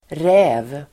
Uttal: [rä:v]